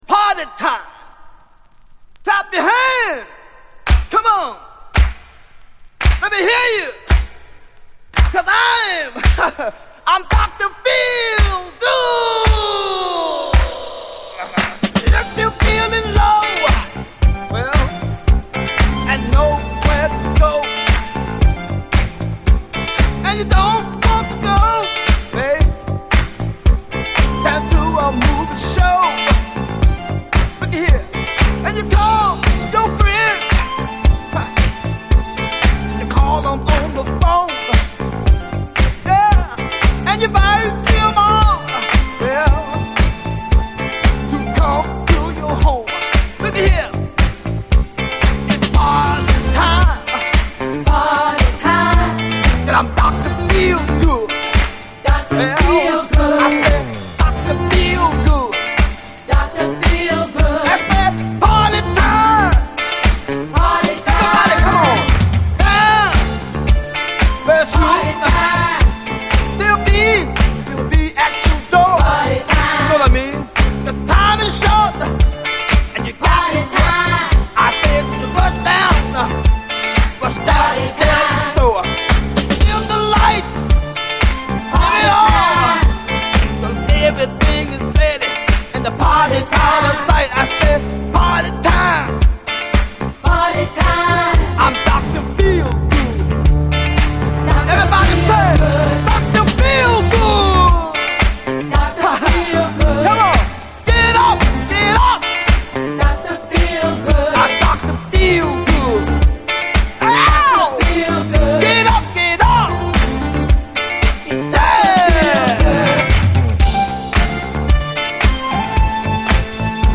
SOUL, 70's～ SOUL, DISCO
B面収録のインスト・ヴァージョンもギター・カッティングが入る別イントロでカッコいい！